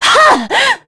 Scarlet-Vox_Attack4.wav